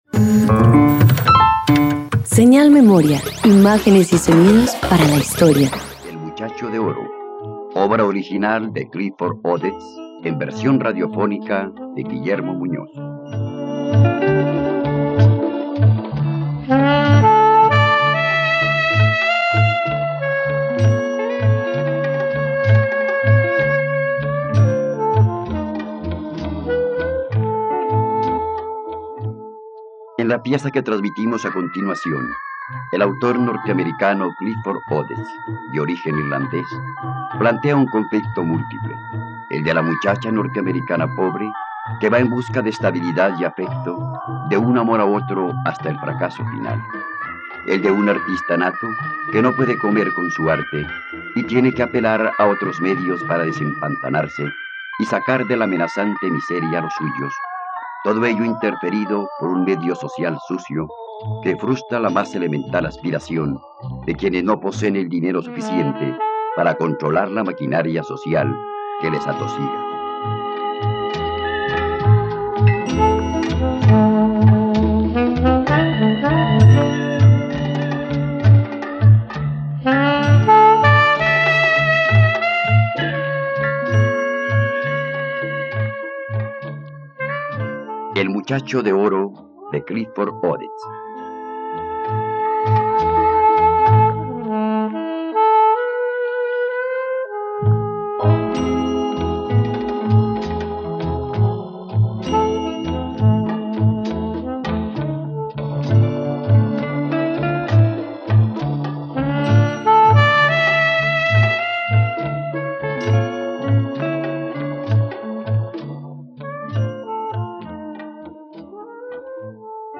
..Radioteatro. Escucha la adaptación radiofónica de “El muchacho de oro” de Clifford Odets por la plataforma streaming RTVCPlay.